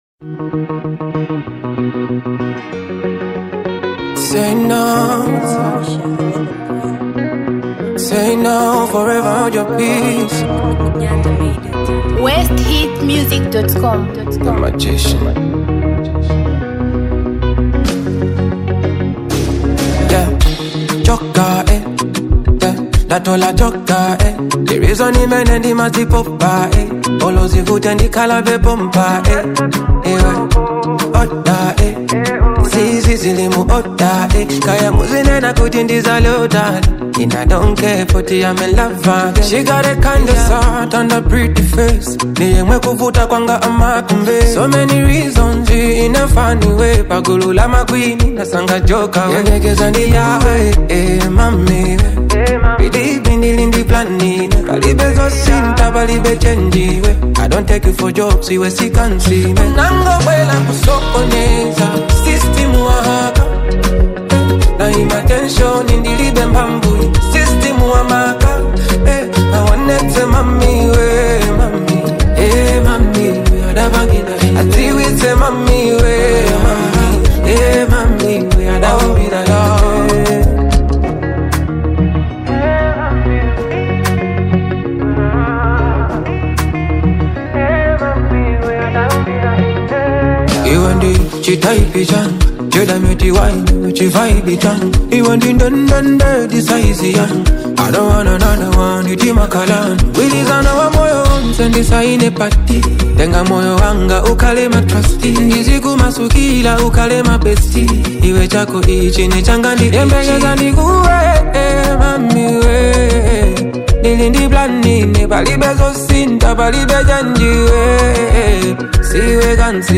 upbeat and playful track